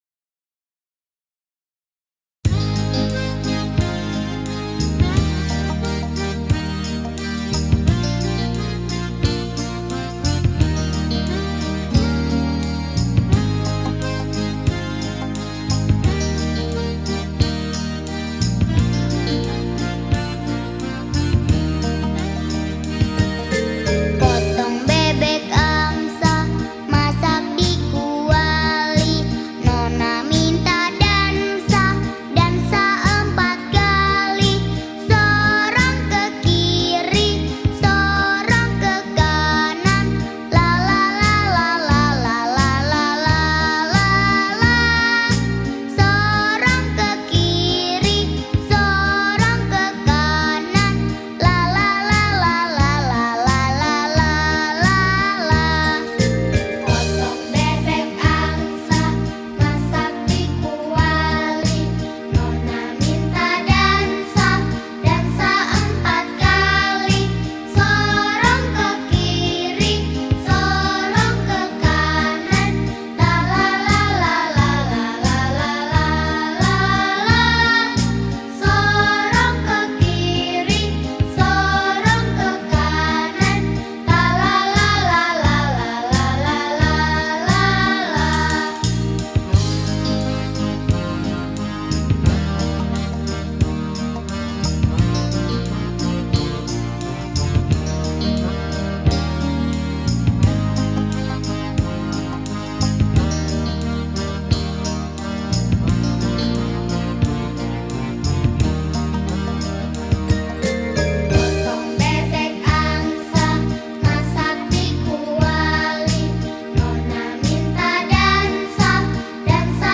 Lagu Kanak-kanak
Indonesia children Song